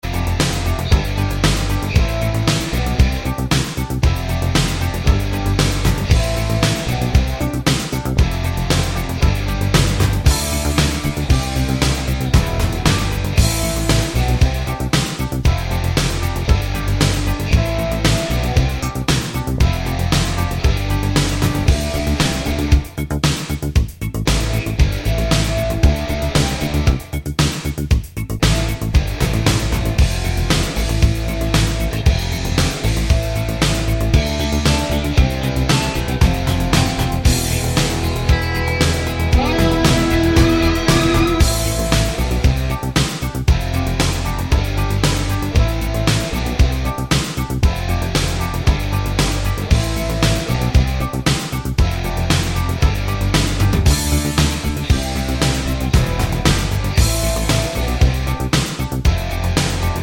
no Backing Vocals Rock 4:53 Buy £1.50